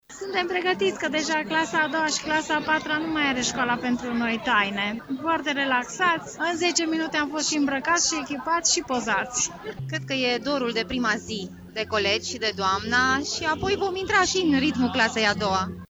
Noul an școlar a început cu emoții și pentru părinții prezenți în curtea Gimnaziului Europa din Tg Mureș:
voxuri-parinti.mp3